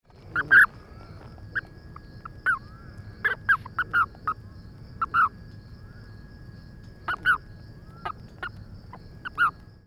Release Calls
Sound This is a 9 second recording of the release calls of a male toad from Riverside County. Insects and advertisement calls are heard in the background.